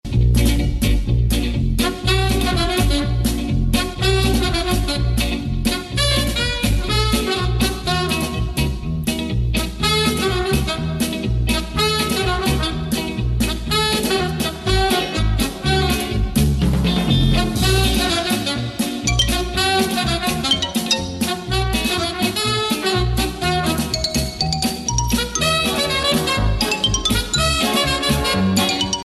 Веселые рингтоны , джаз , инструментальные